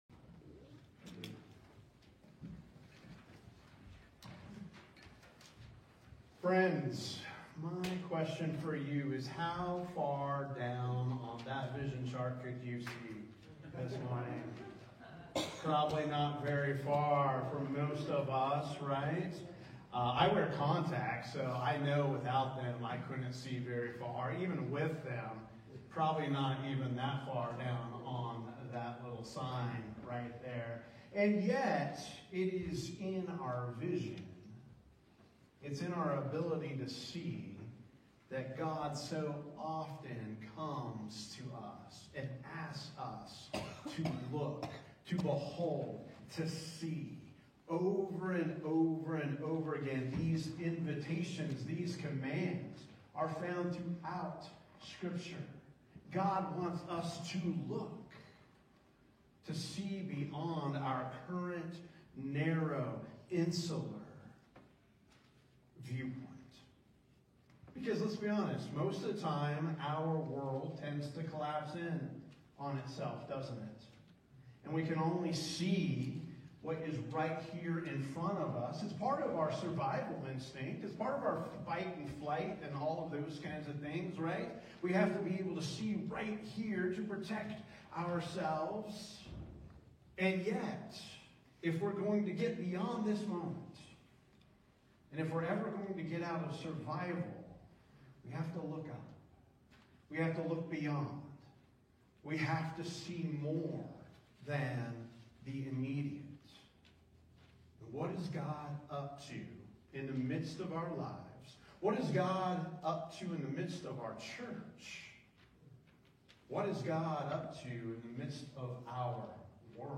11/9/25 Sermon: Space for Vision